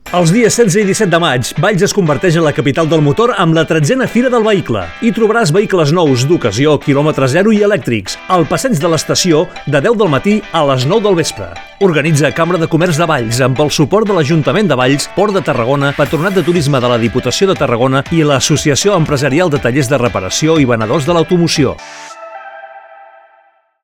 falca-vehicle-cambra-valls.mp3